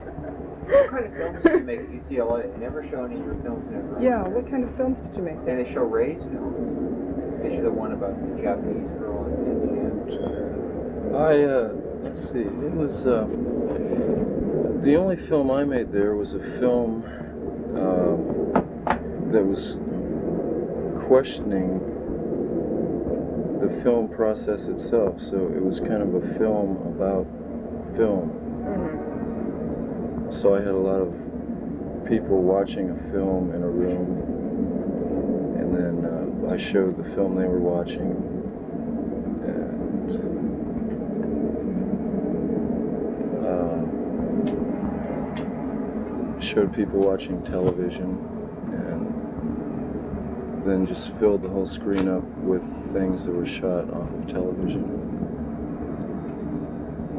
The Doors/The Lost Interview Tapes Featuring Jim Morrison - Volume Two The Circus Magazine Interview (Album)